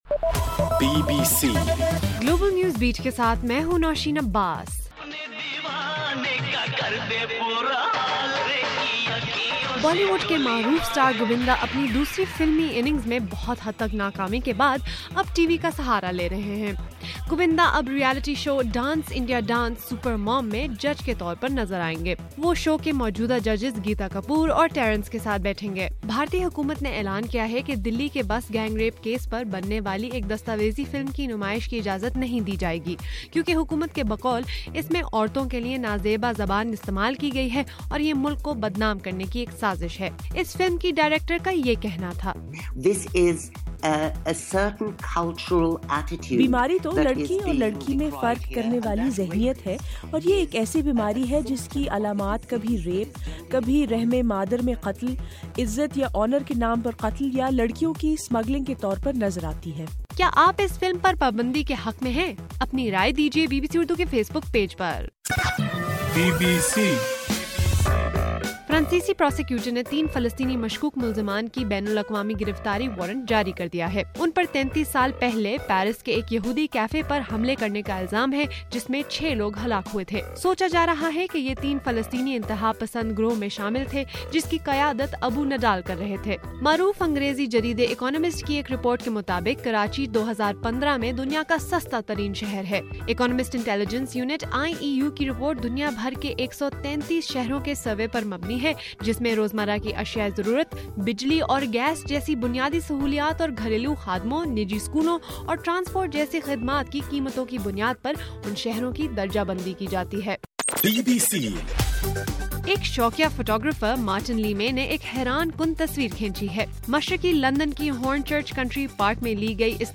مارچ 4: رات 10 بجے کا گلوبل نیوز بیٹ بُلیٹن